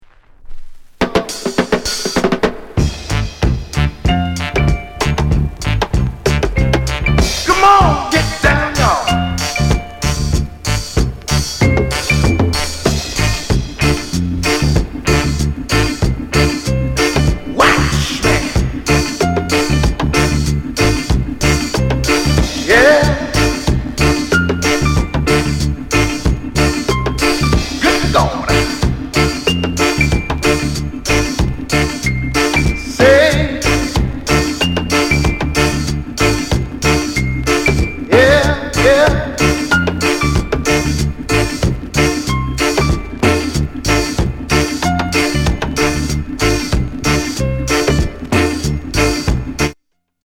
FUNKY REGGAE